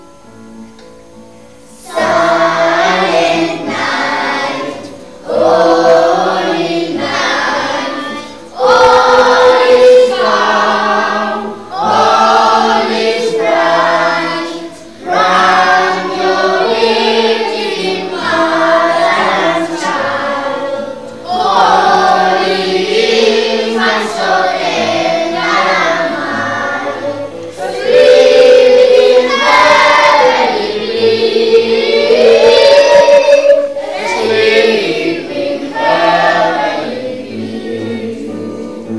El Cisneros interpreta un villancico
Por alumnos de 3ºB del CP Cisneros de Santander.